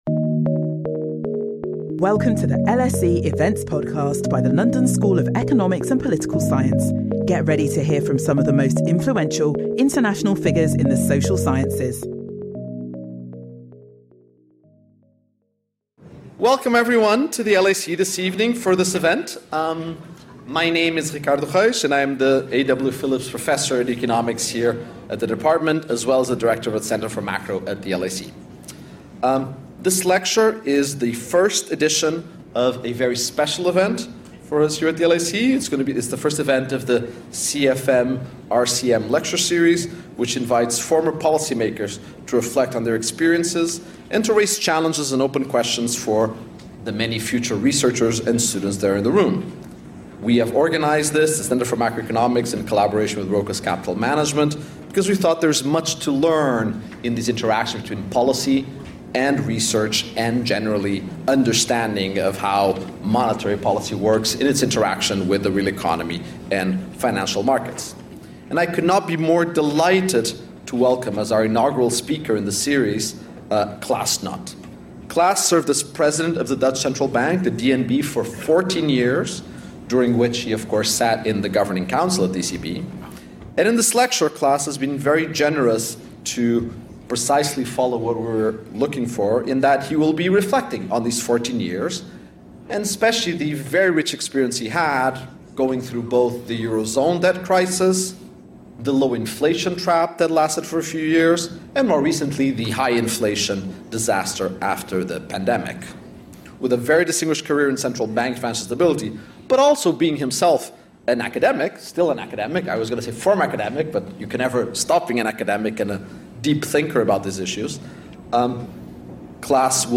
In this lecture, Klaas will break down his tenure into three distinct phases: the Eurozone debt crisis, the low-inflation trap, and the recent high-inflation shock.